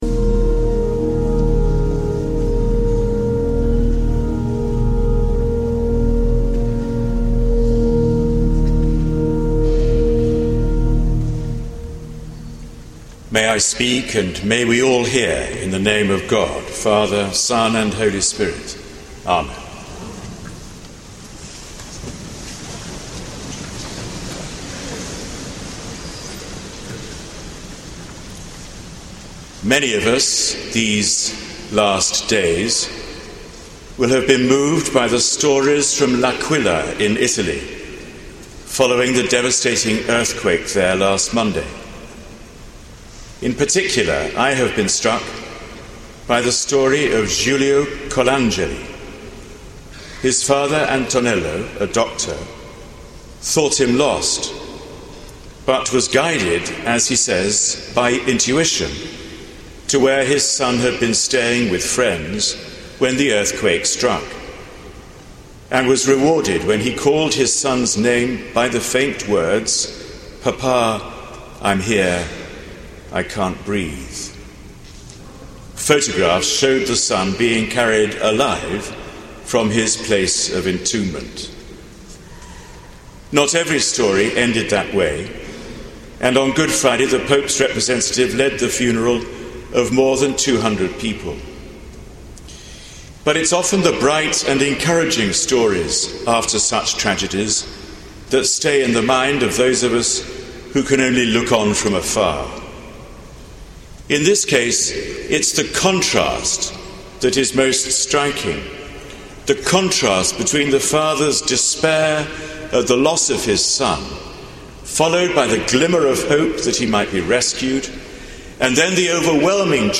Sermon given on Easter Day by the Dean of Westminster, The Very Reverend Dr John Hall.